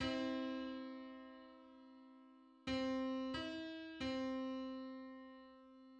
Just: 315/256 = 359.05 cents.
Public domain Public domain false false This media depicts a musical interval outside of a specific musical context.
Three-hundred-fifteenth_harmonic_on_C.mid.mp3